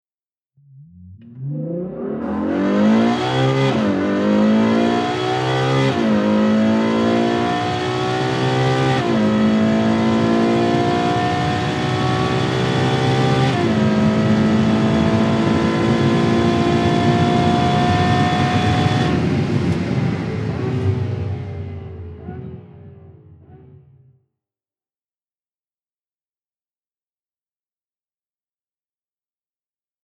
Рев двигателя Ferrari 458 Spider